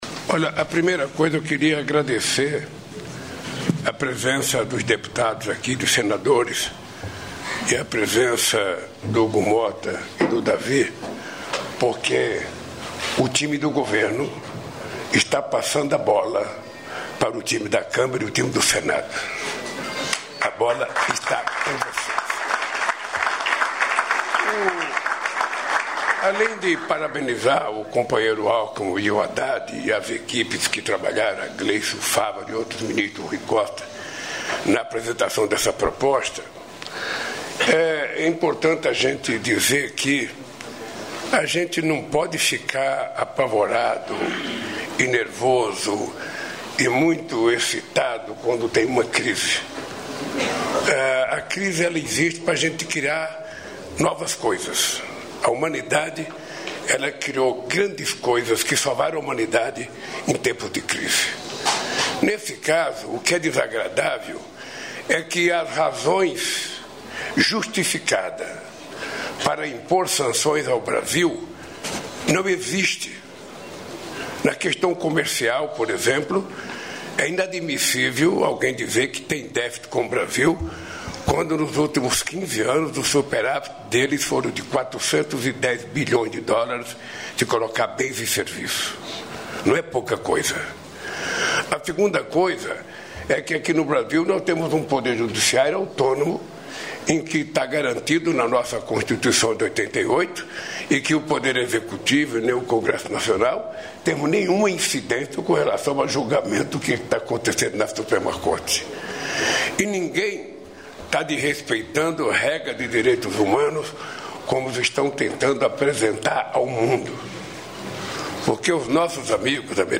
Íntegra do discurso do presidente da República Luiz Inácio Lula da Silva na cerimônia de anúncios de investimentos do Governo Federal para o estado de Rondônia, nesta sexta-feira (8), em Porto Velho (RO).